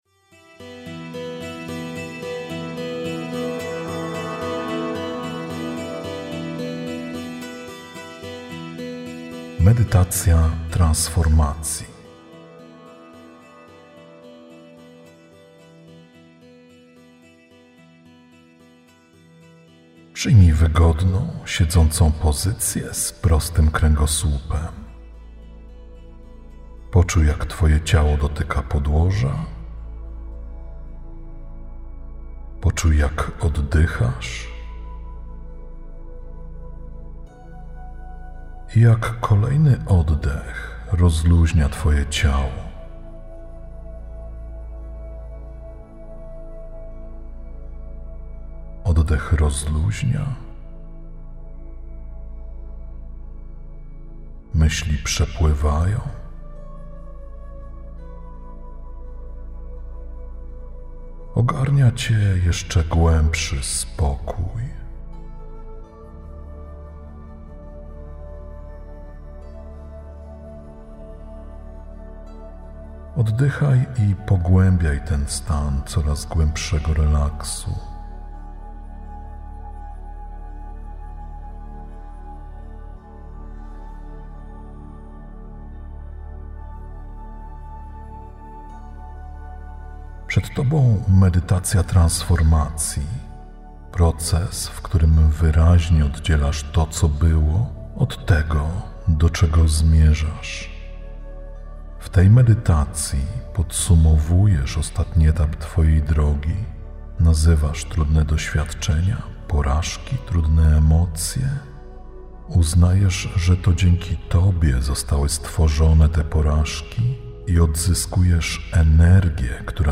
Zawiera lektora: Tak
Medytacja-Transformacji_sample.mp3